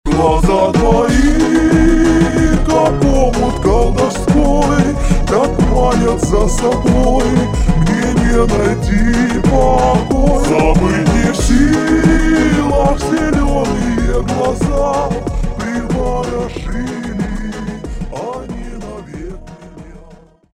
• Качество: 320, Stereo
мужской вокал